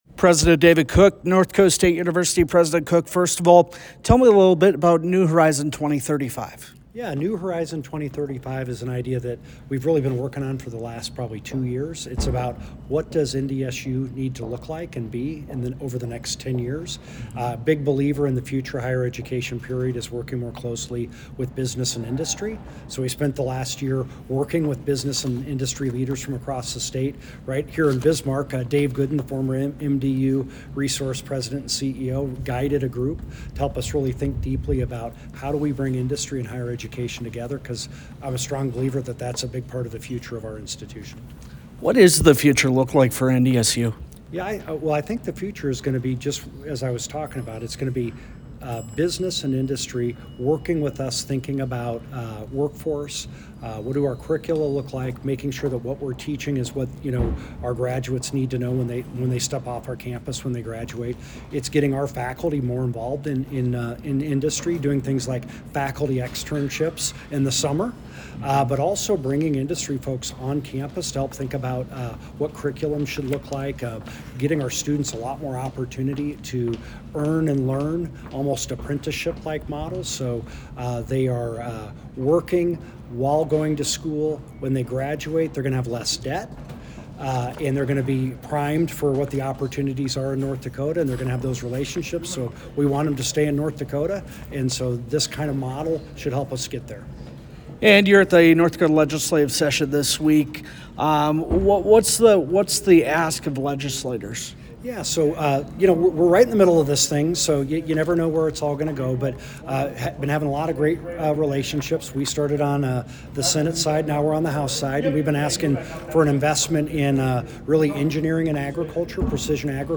Interview with NDSU President Dr. David Cook